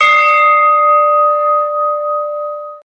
bellSound.mp3